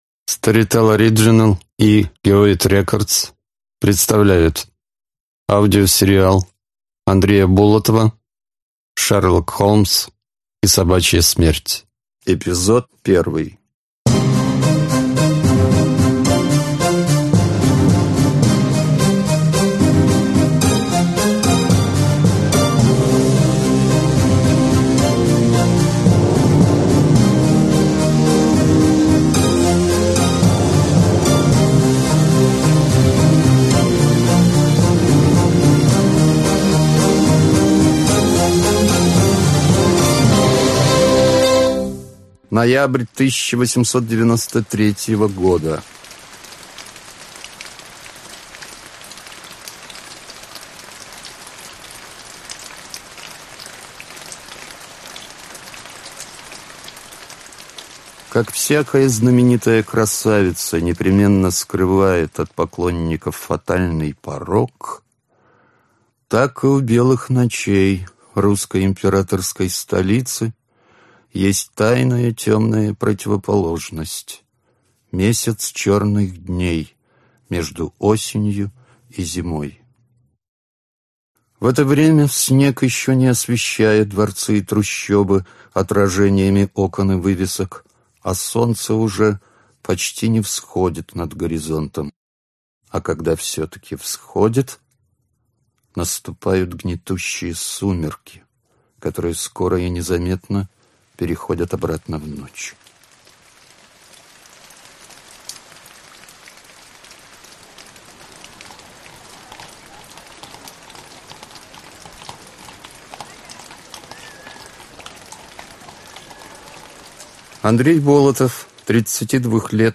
Аудиокнига Шерлок Холмс и Собачья смерть | Библиотека аудиокниг